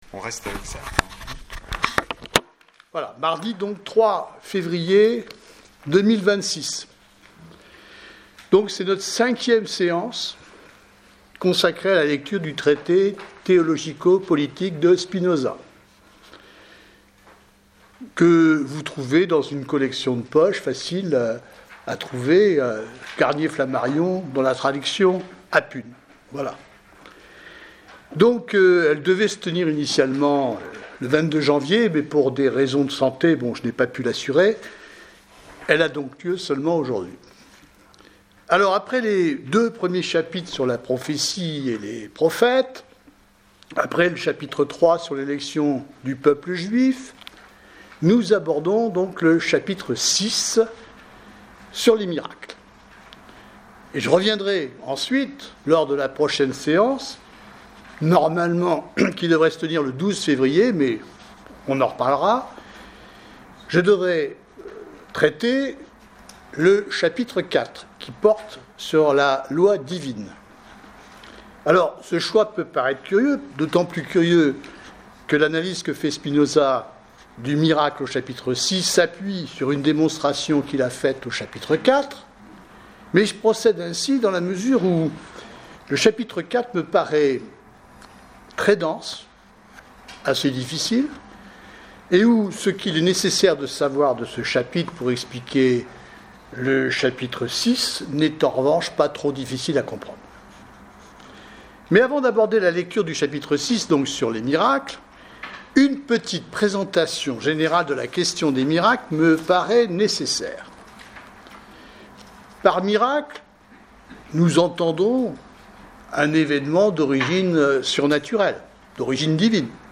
1/ Enregistrement de la séance du 03 février 2026